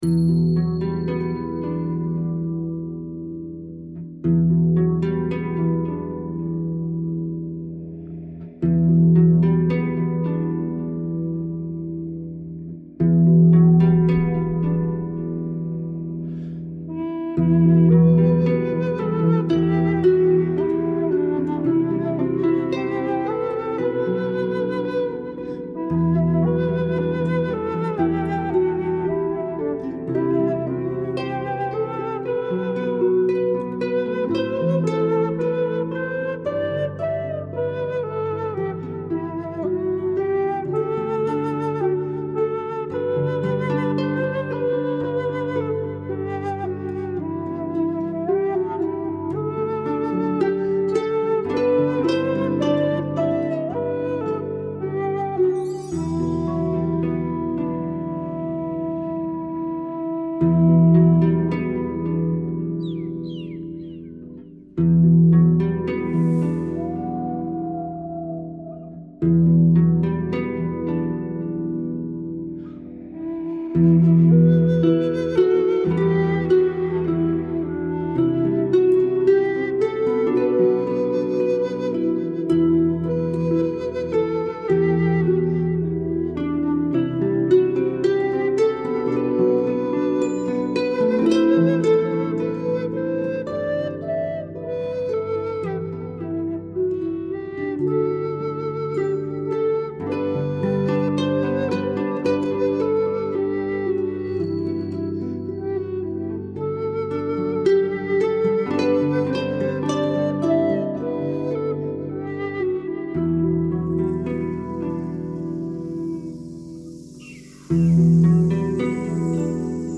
playing two recorders in harmony